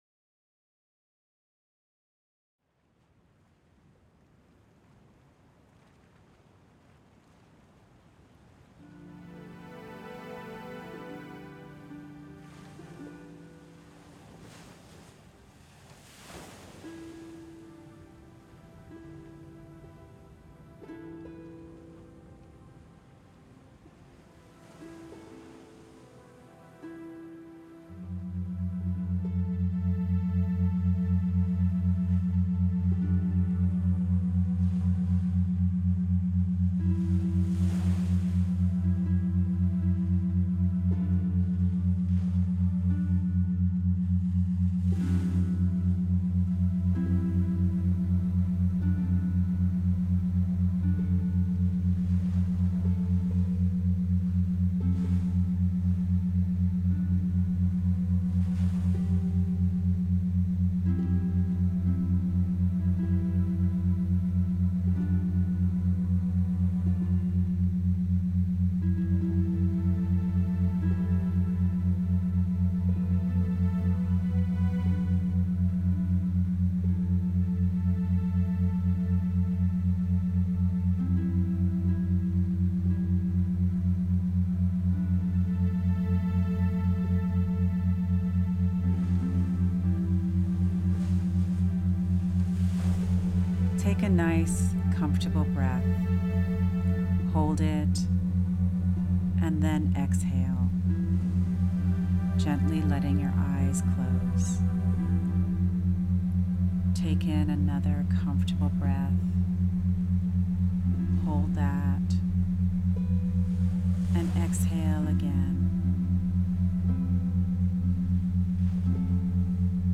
I am pleased to share this Guided Mediation with you — it is loosely based on Native American lore. The Meditation is designed to help you become calm and centered, and return to your Self. I would recommend that you listen with headphones on for best results.